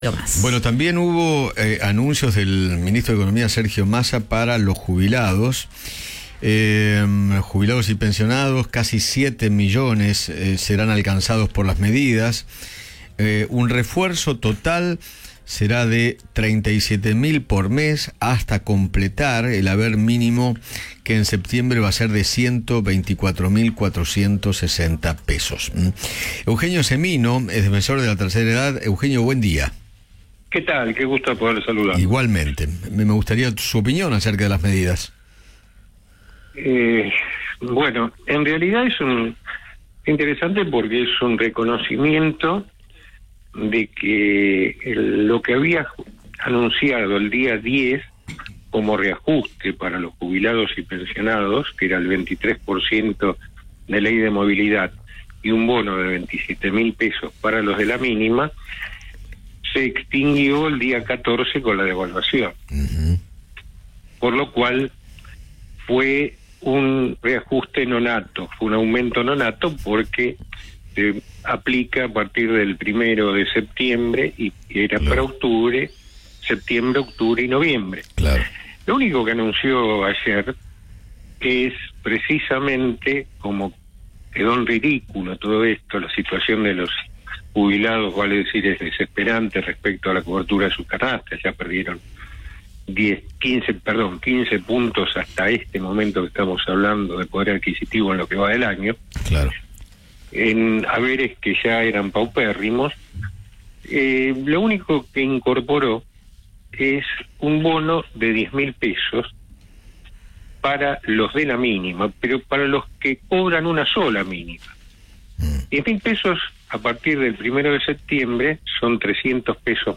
Eugenio Semino, defensor de la Tercera Edad, conversó con Eduardo Feinmann sobre las medidas que anunció Sergio Massa para los jubilados y pensionados.